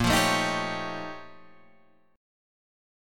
A# Minor Major 13th